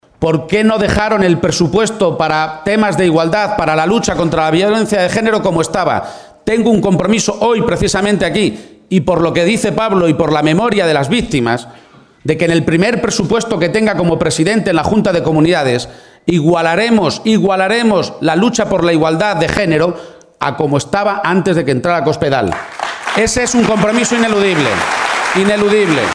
Asume cuatro compromisos firmes en su primer acto de campaña electoral, celebrado en Guadalajara